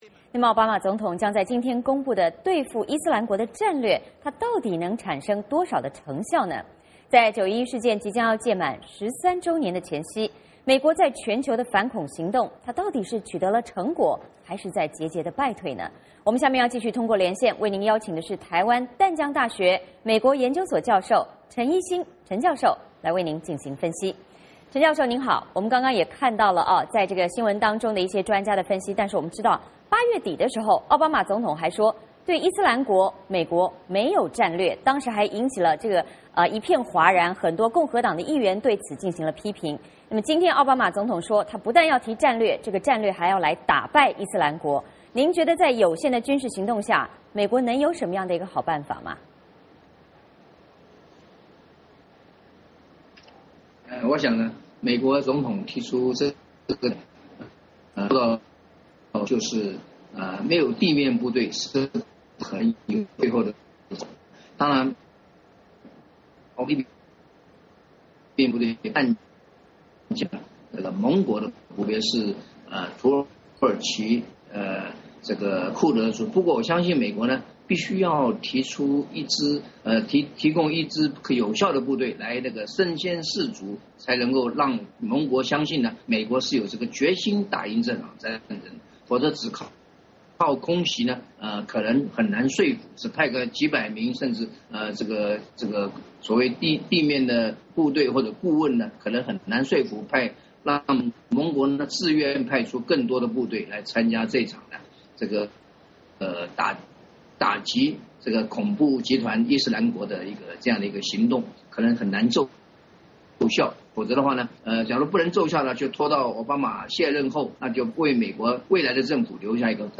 VOA连线：911前夕，奥巴马公布打击伊斯兰国战略